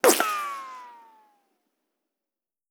flintlock_miss.wav